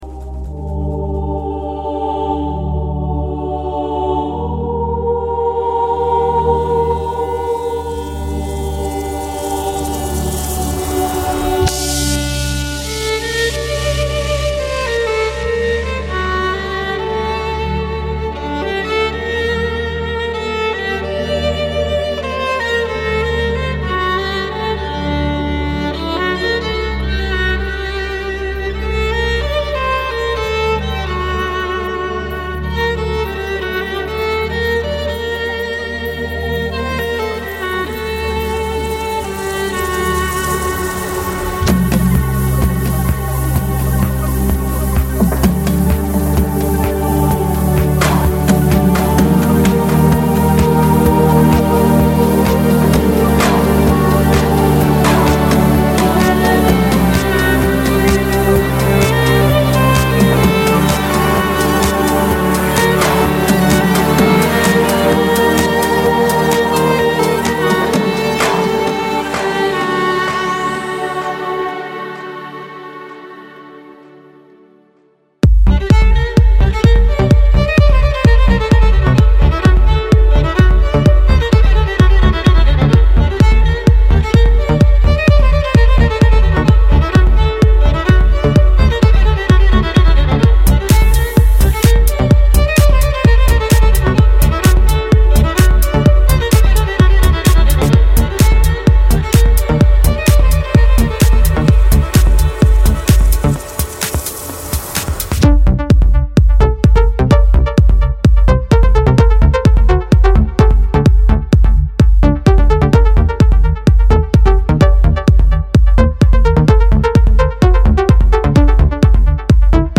Skripka_Klassikavsovremennoqobrabotke_smyslpesni_.mp3